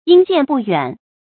yīn jiàn bù yuǎn
殷鉴不远发音
成语注音 ㄧㄣ ㄐㄧㄢˋ ㄅㄨˋ ㄧㄨㄢˇ